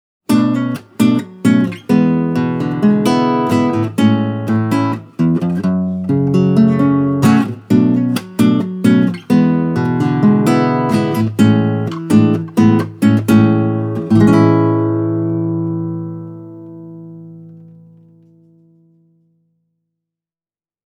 The Admira A5 has a big voice with an even balance between its chunky bottom end and clear treble attack.